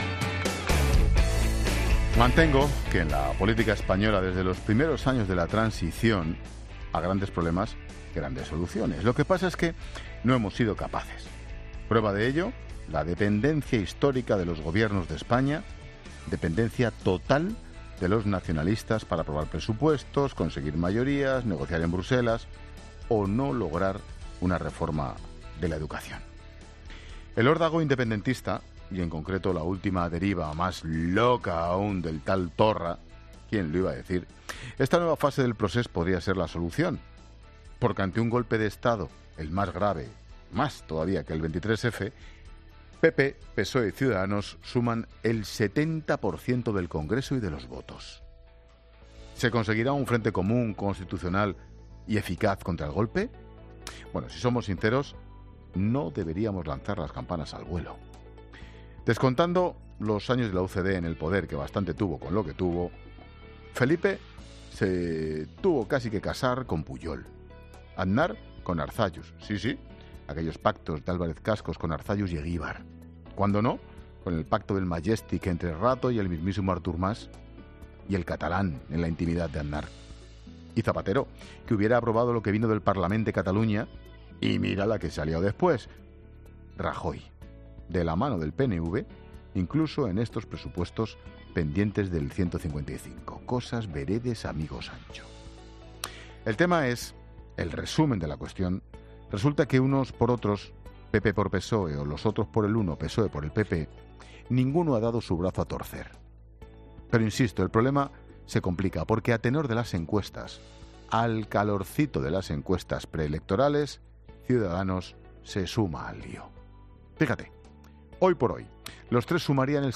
Monólogo de Expósito
El comentario de Ángel Expósito.